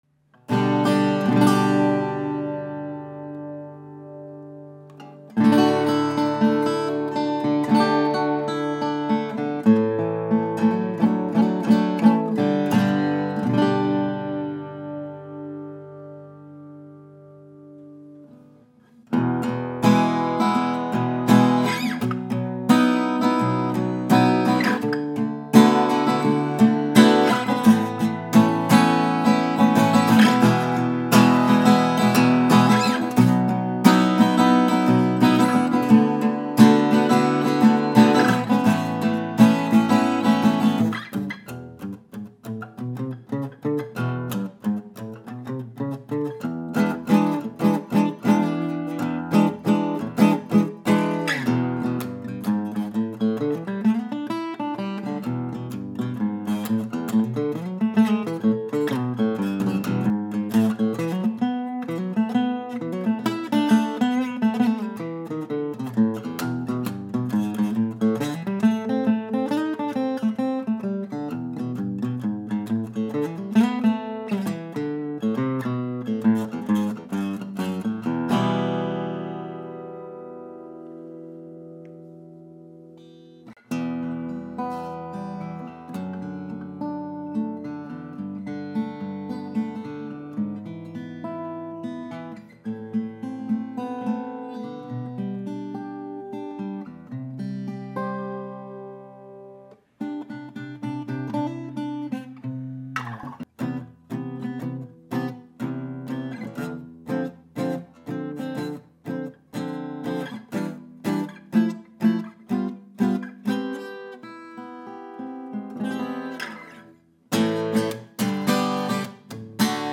Wonderful Martin 0-18 Standard Series, built with Genuine Mahogany and Sitka Spruce.
And still, by today’s standard, this guitar is commanding with her punchy, present voice!
Overall, she provides the classic legendary Martin guitar tone.